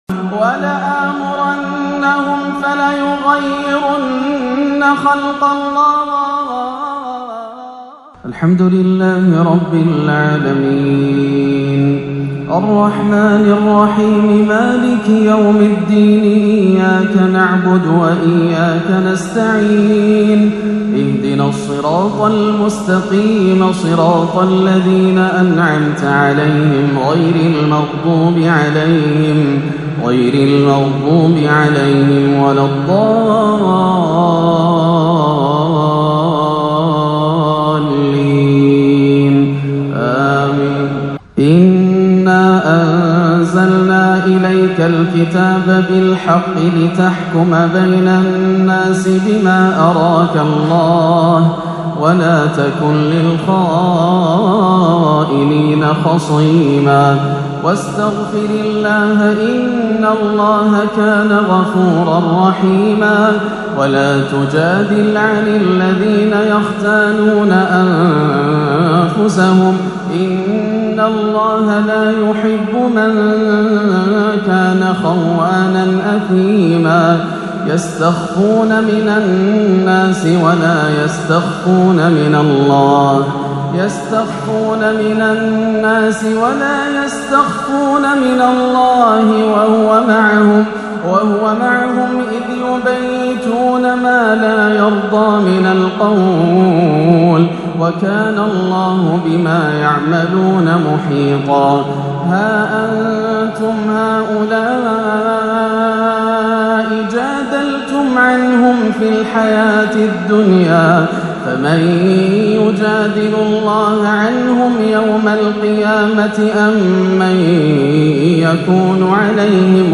( يَعِدُهُم وَيُمَنّيهِم ) تلاوة خيالية أخاذة خاشعة تسلب الألباب - عشاء الإثنين 14-8 > عام 1439 > الفروض - تلاوات ياسر الدوسري